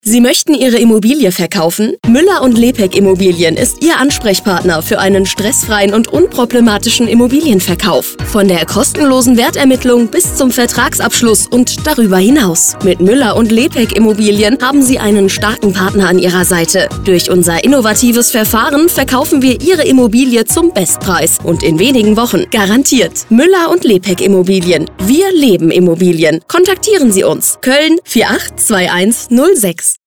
Radio Spot
Radiospot.mp3